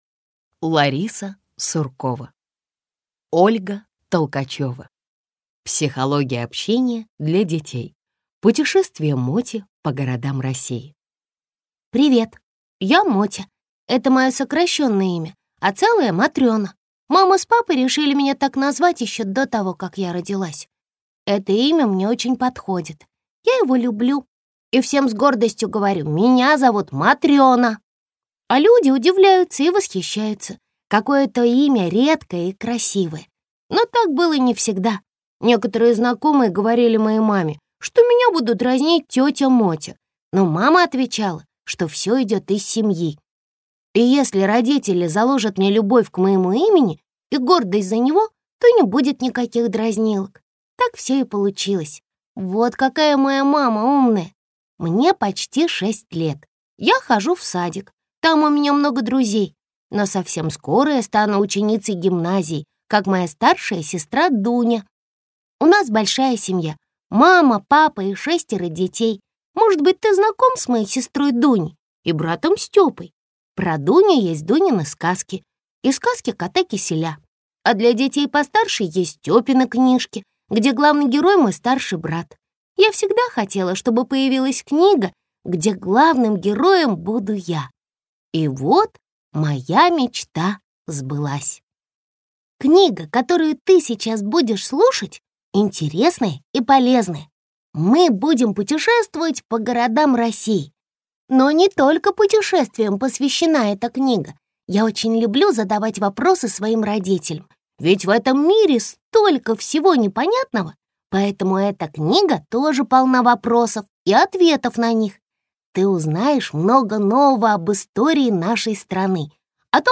Аудиокнига Психология общения для детей. Путешествие Моти по городам России | Библиотека аудиокниг